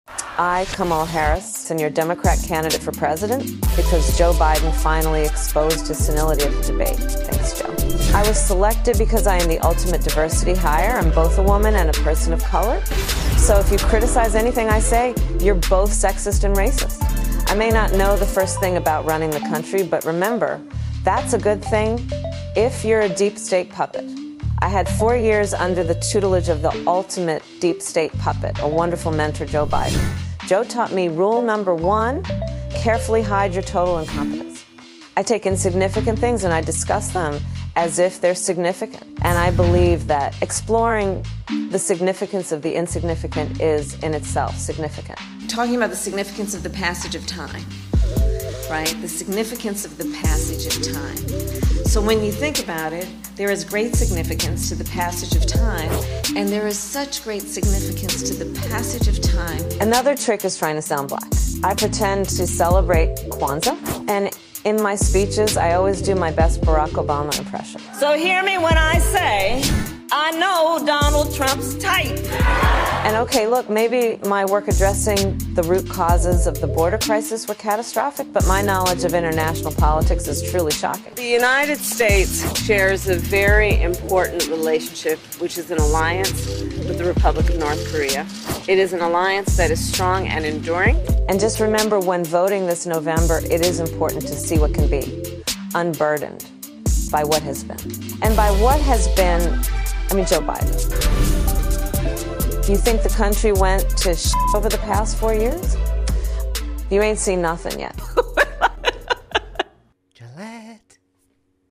kamala-fake.mp3